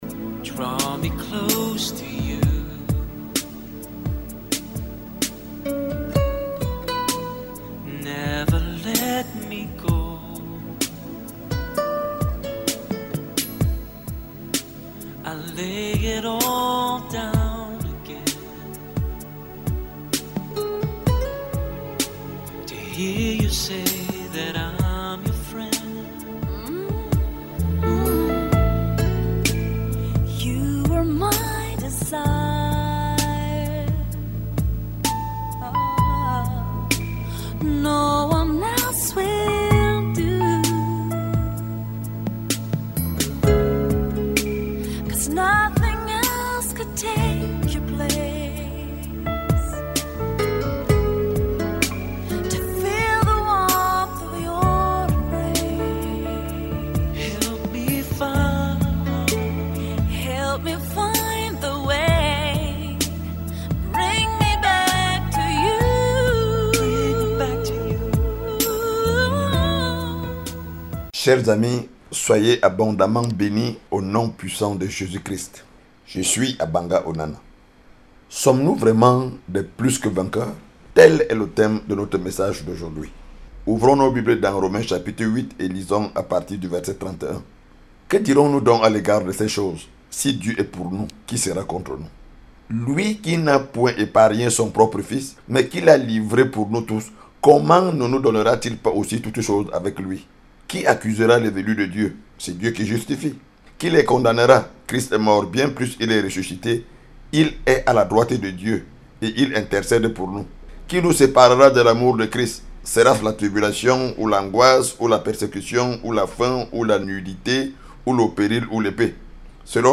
← Back to teachings 📥 Download audio Are we really more than victorious?